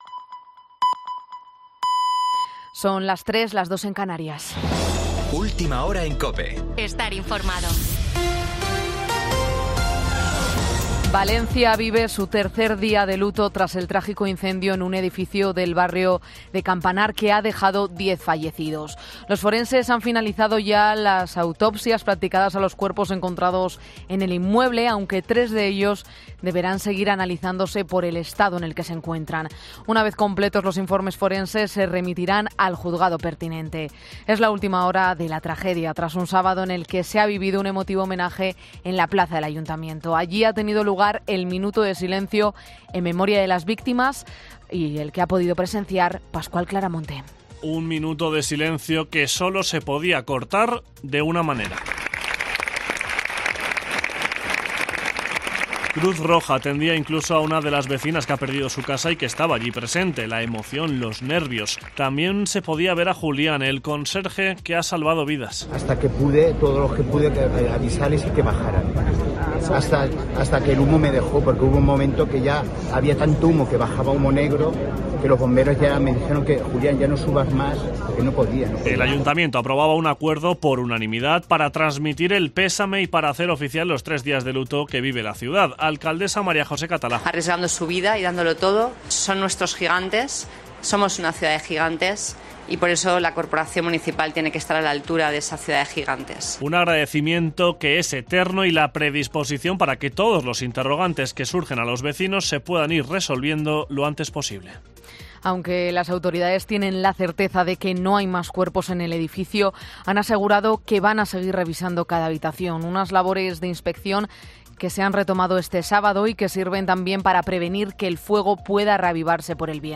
Boletín 03.00 horas del 25 de febrero de 2024